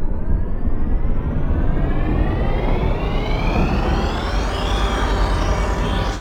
plasmacannonpowerup.ogg